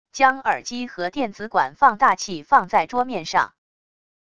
将耳机和电子管放大器放在桌面上wav音频